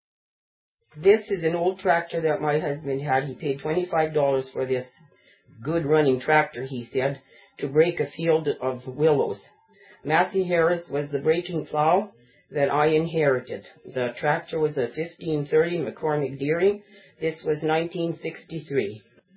1530 McCormick Dearing Tractor - Sound Clip